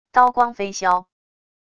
刀光飞削wav音频